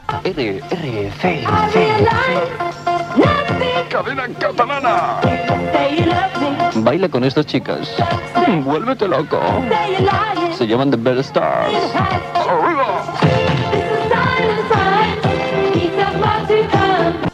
Presentació d'un tema musical.
Musical